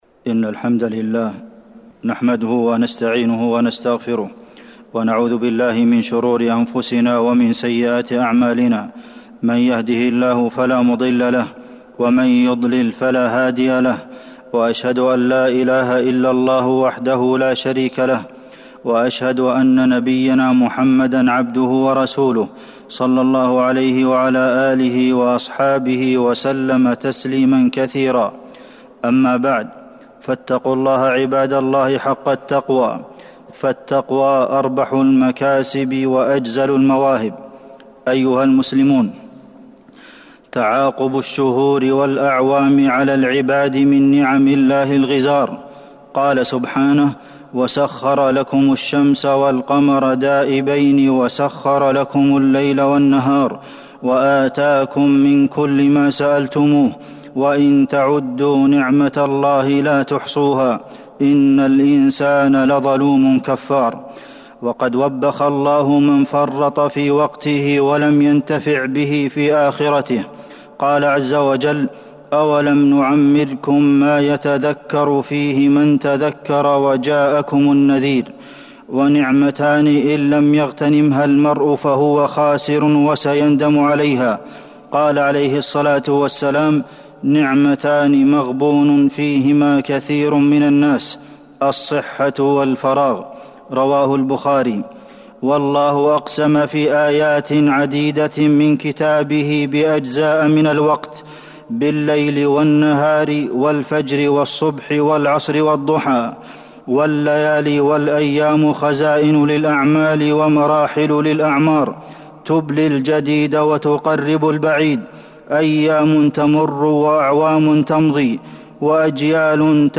تاريخ النشر ١٧ ذو الحجة ١٤٤١ هـ المكان: المسجد النبوي الشيخ: فضيلة الشيخ د. عبدالمحسن بن محمد القاسم فضيلة الشيخ د. عبدالمحسن بن محمد القاسم محاسبة النفس